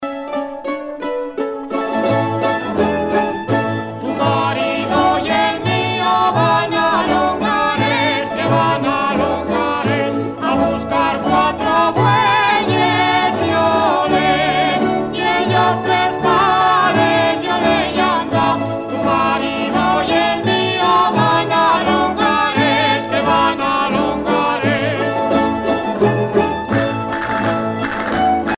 danse : boléro ;
Alto Aragon (Grupo folklorico)
Pièce musicale éditée